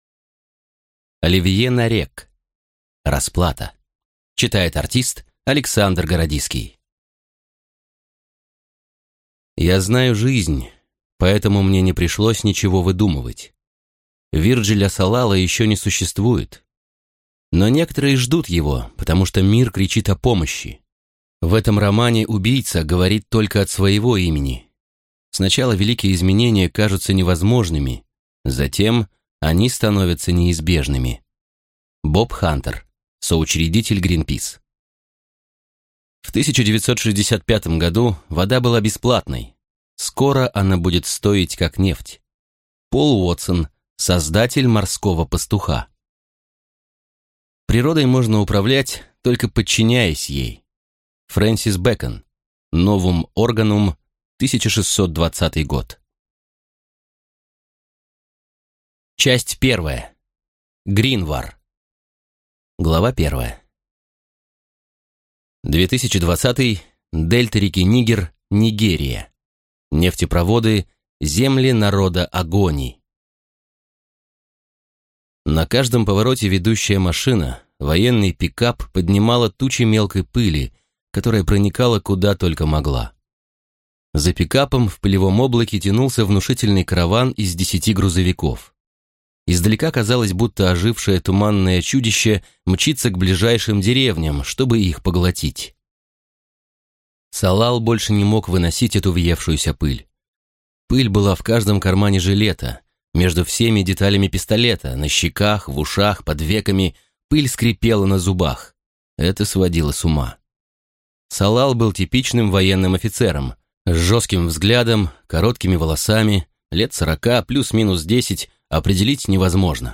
Аудиокнига Расплата | Библиотека аудиокниг
Прослушать и бесплатно скачать фрагмент аудиокниги